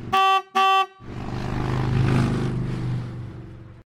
motorcycle-horn.ogg